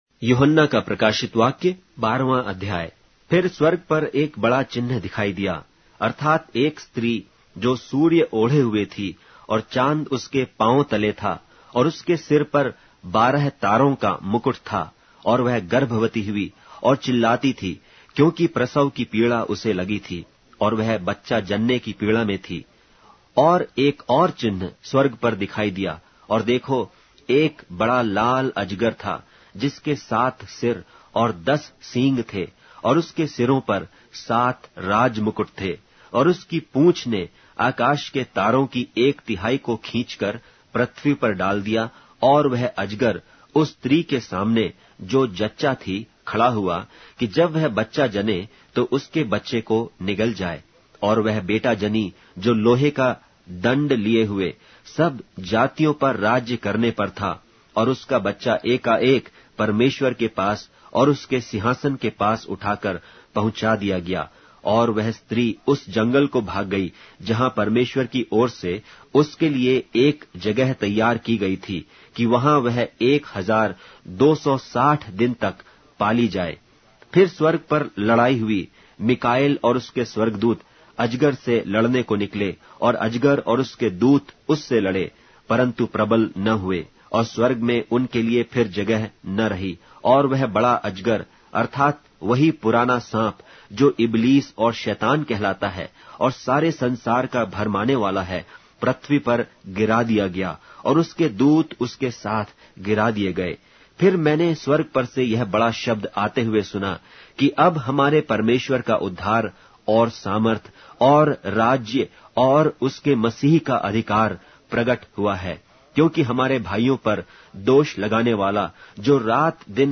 Hindi Audio Bible - Revelation 1 in Irvur bible version